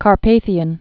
(kär-pāthē-ən)